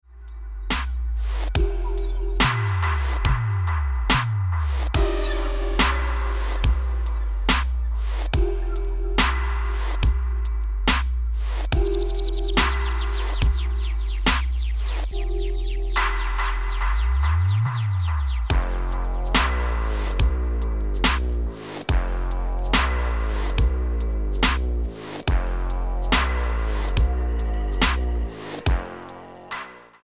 The dub-step movement continues